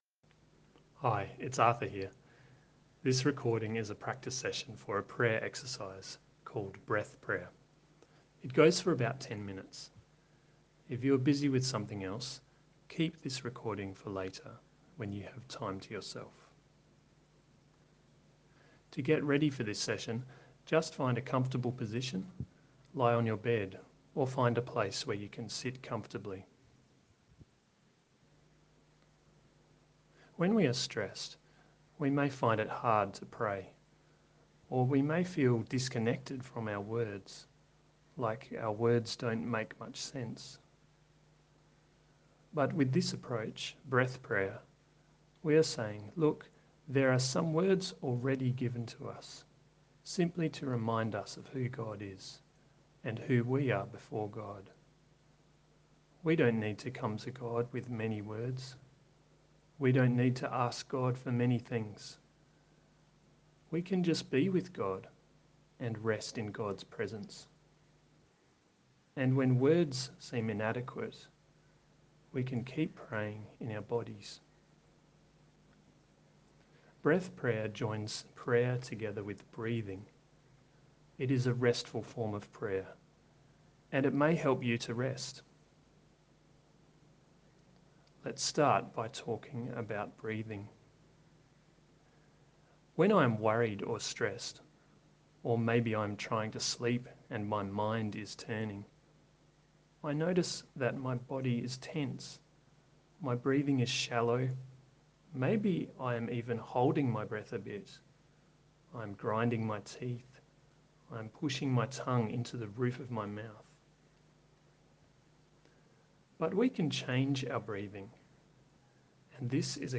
I recorded a prayer exercise known as breath prayer (heart prayer).